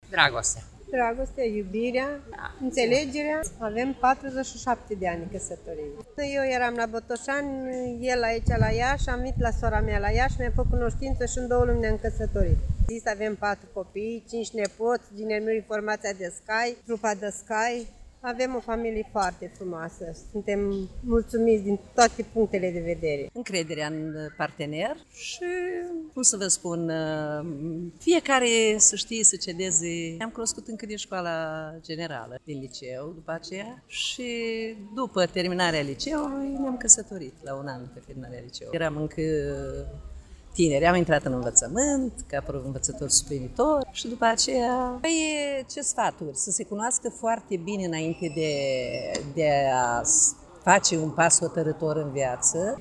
AUDIO. Reportaj. Și a fost „Ziua Familiei” la Iași